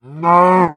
1.21.5 / assets / minecraft / sounds / mob / cow / hurt3.ogg
hurt3.ogg